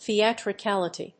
/θiˌætrʌˈkælʌˌti(米国英語), θi:ˌætrʌˈkælʌˌti:(英国英語)/
音節the･at･ri･cal･i･ty発音記号・読み方θiæ̀trɪkǽləti